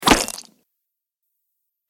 دانلود آهنگ دعوا 41 از افکت صوتی انسان و موجودات زنده
دانلود صدای دعوا 41 از ساعد نیوز با لینک مستقیم و کیفیت بالا
جلوه های صوتی